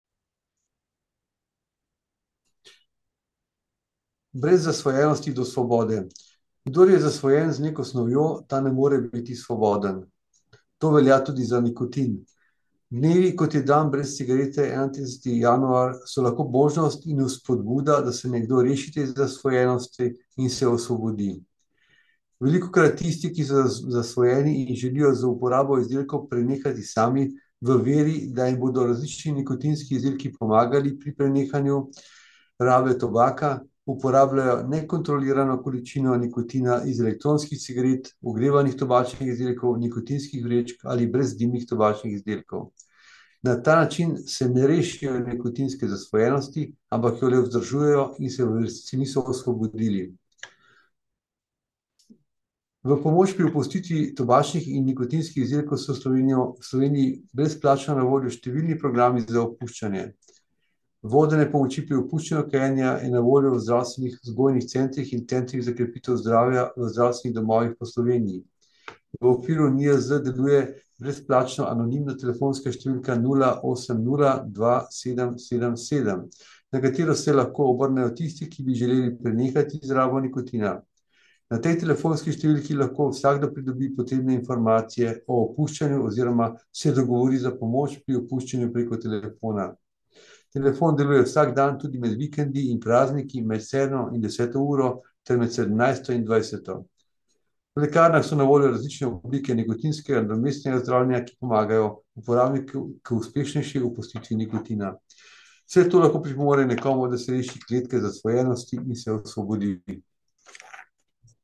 Avdio izjave: